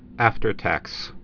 (ăftər-tăks)